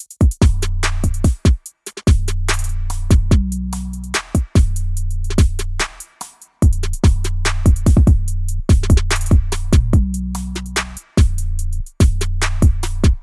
描述：用omnisphere和nexus制作的（低音音符GGAA）
声道立体声